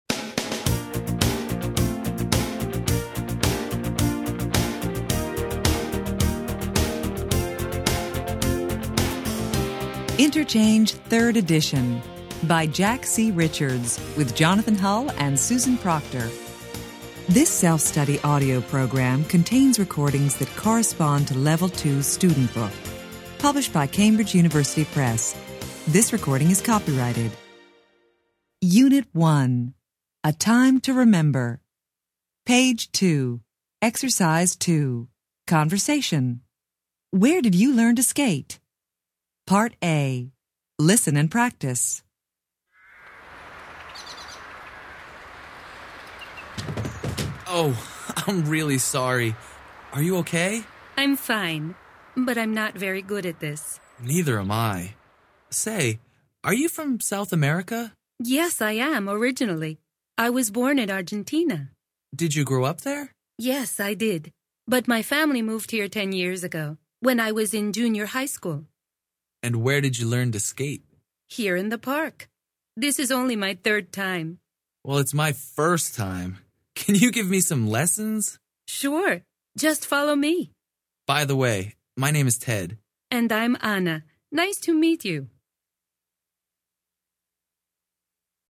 interchange3-level2-unit1-ex2-conversation-track1-students-book-student-arcade-self-study-audio.mp3